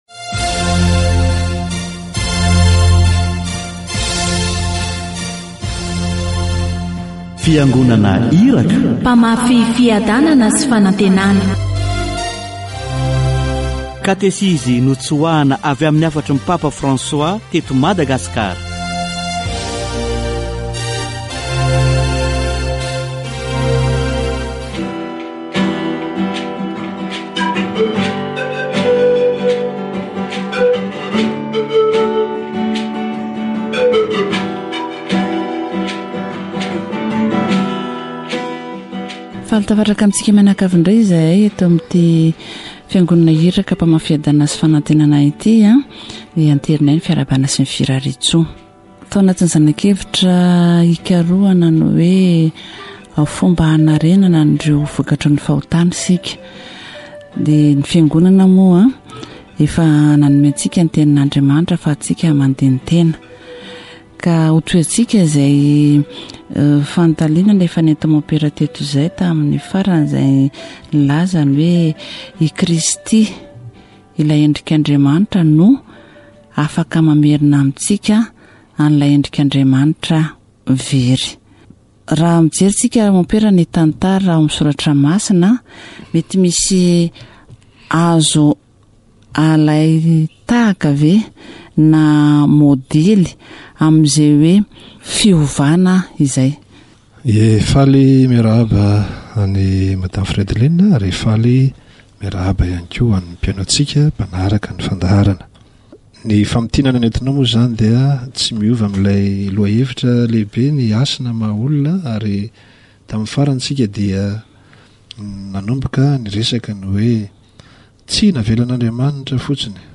Many Bible stories speak of this "Encounter", including the story of Saul, he was converted and repented and became Paul. Repentance is the way back to human dignity. Catechesis on human dignity